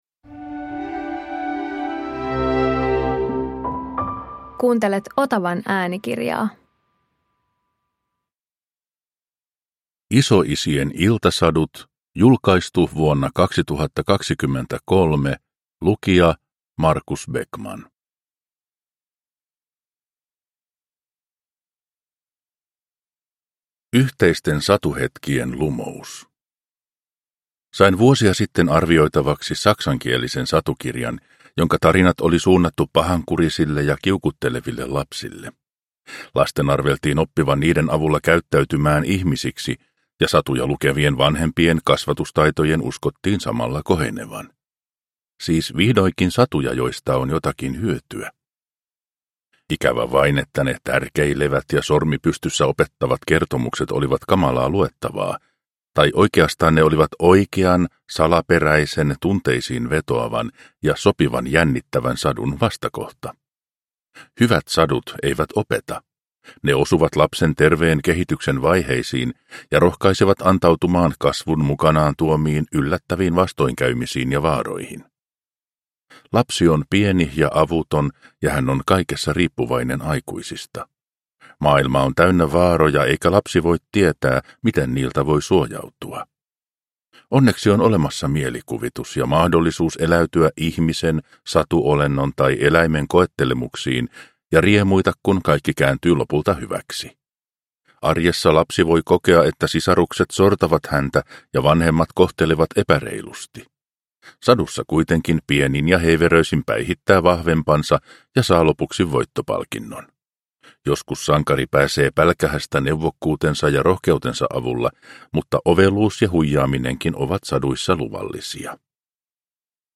Isoisien iltasadut – Ljudbok – Laddas ner